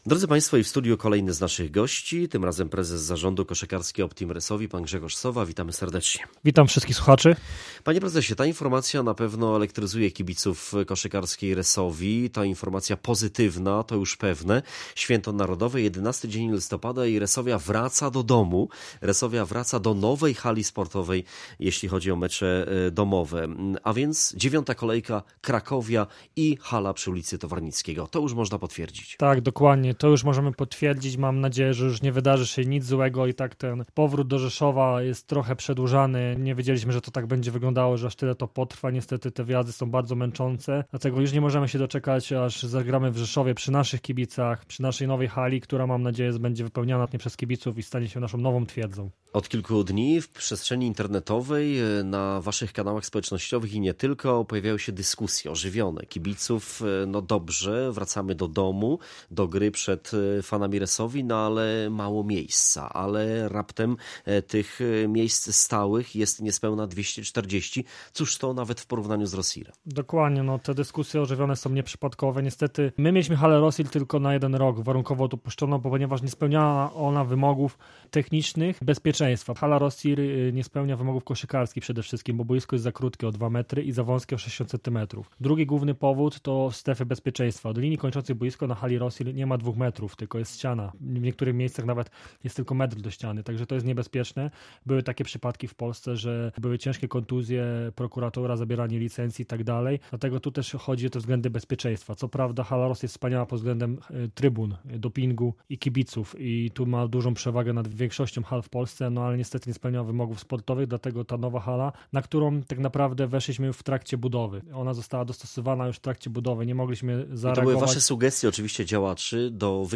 Koszykówka – wywiad